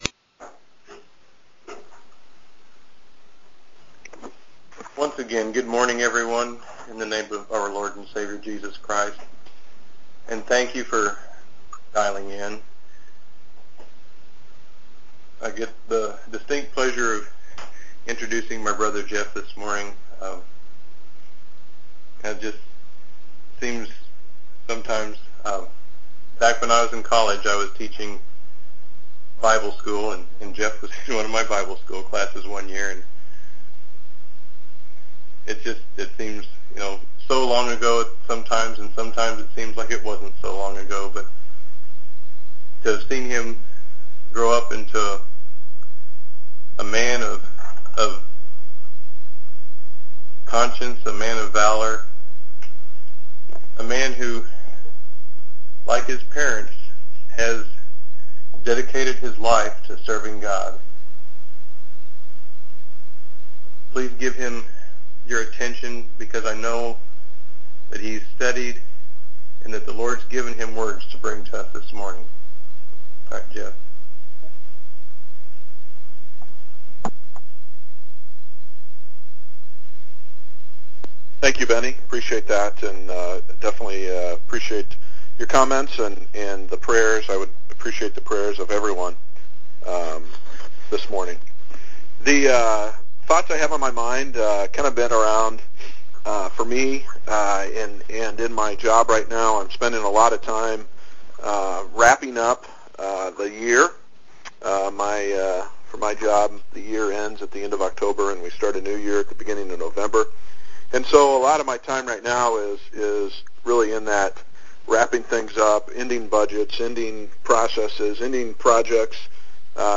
9/26/2010 Location: Teleconference Event